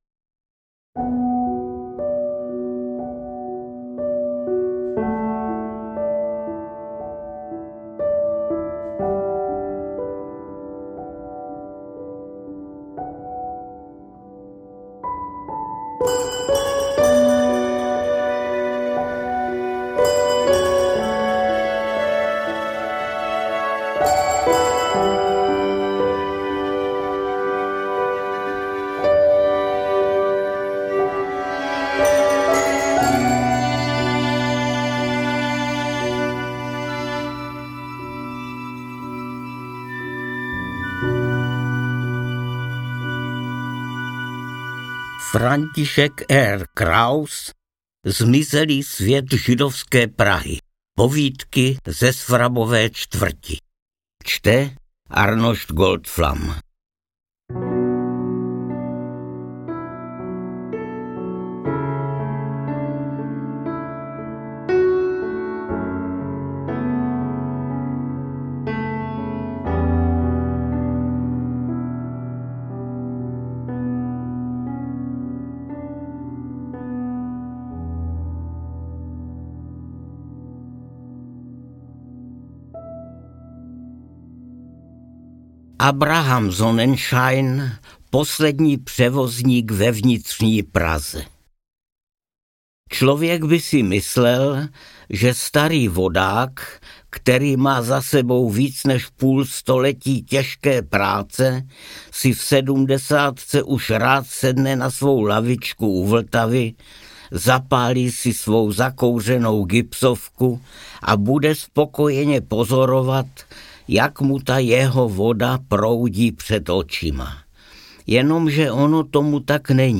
Interpret:  Arnošt Goldflam